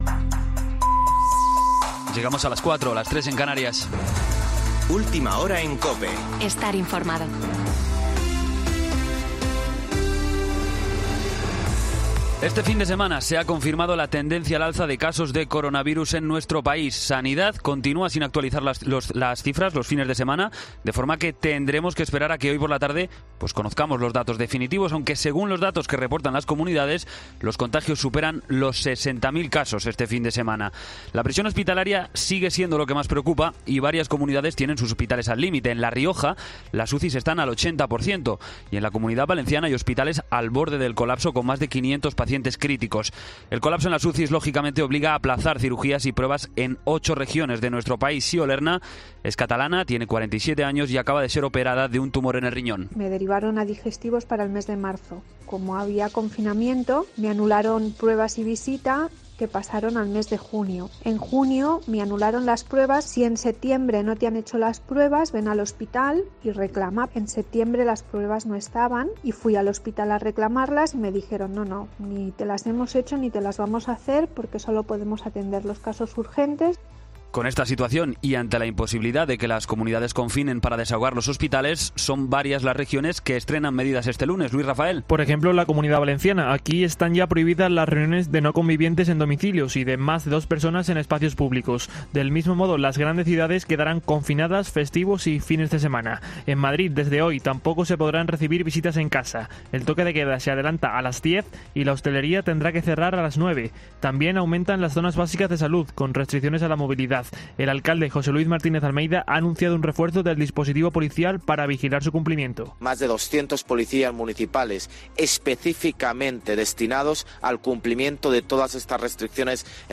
Boletín de noticias COPE del 25 de enero de 2021 a las 04.00 horas